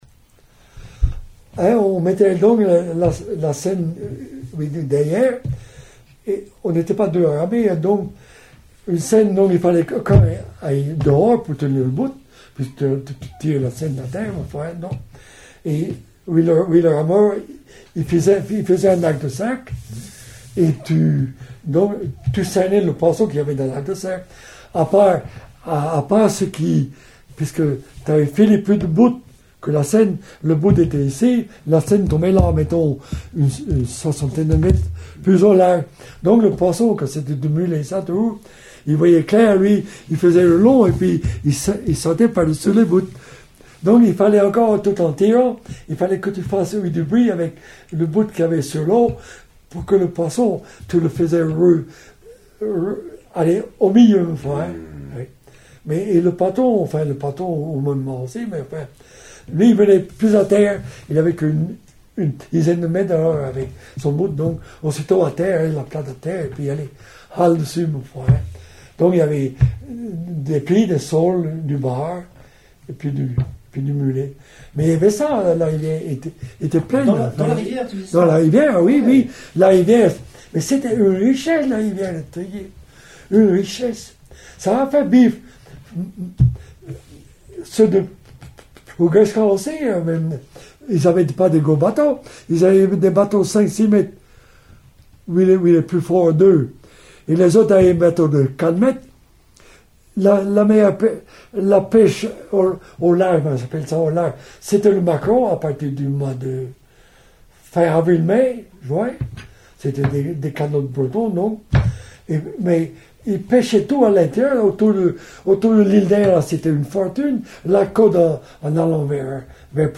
Témoignage oral - Traditions goëmonières I